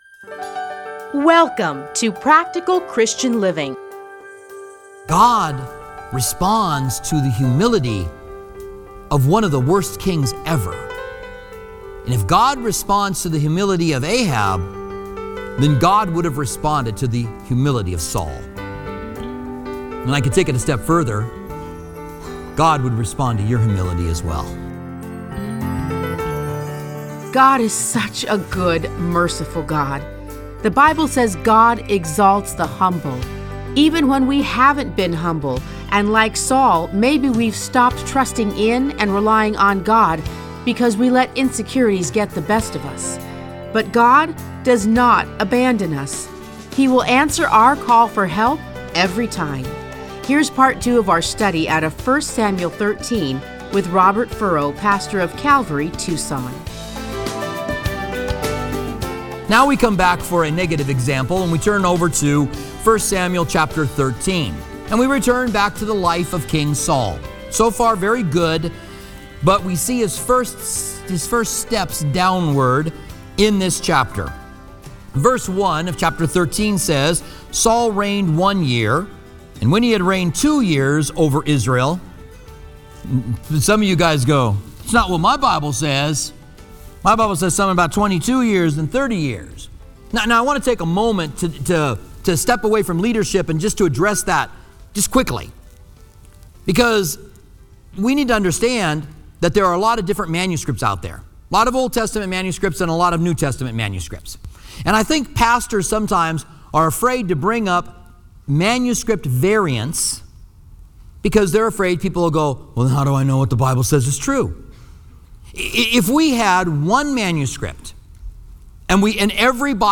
Listen to a teaching from 1 Samuel 13:1-15.